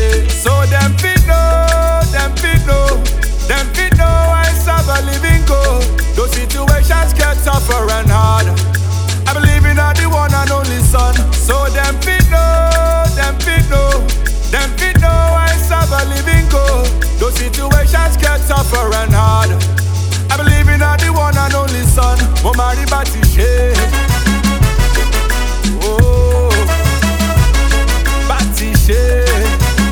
• Afro-Pop